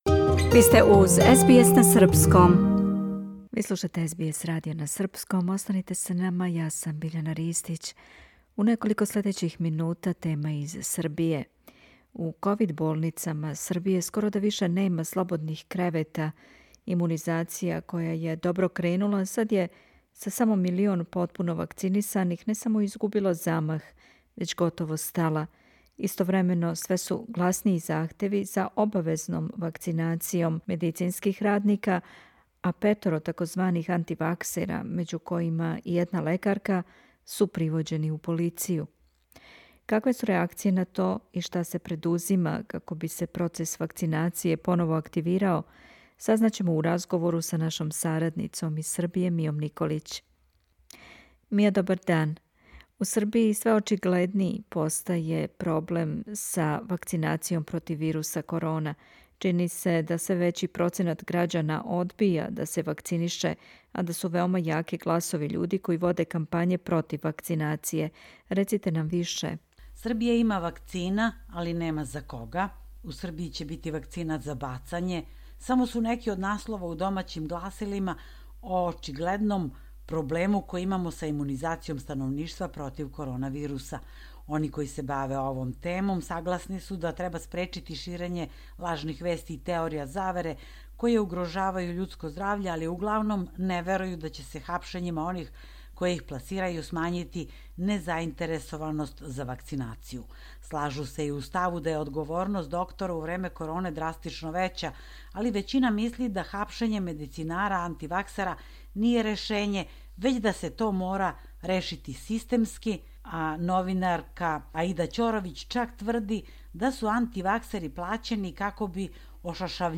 Какве су реакције на то и шта се предузима како би се процес вакцинације поново активирао, сазнајемо у разговору са нашом сарадницом из Србије